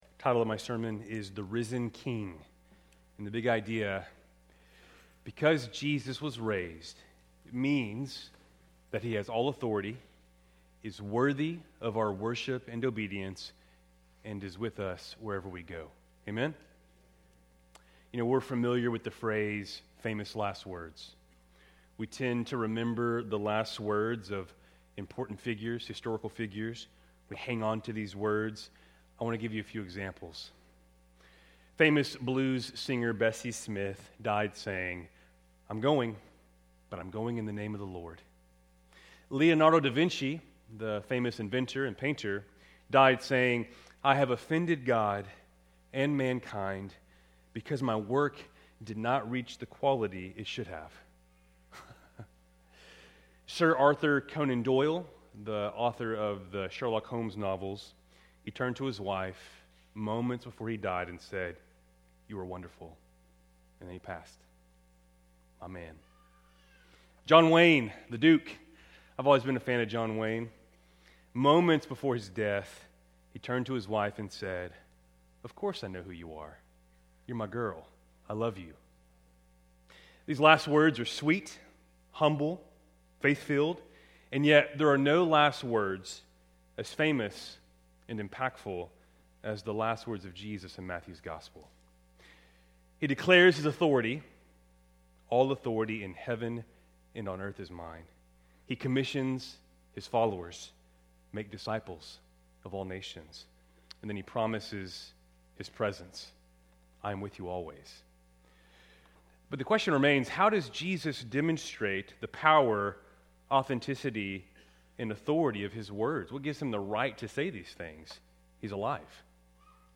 Keltys Easter Worship Service, April 5, 2026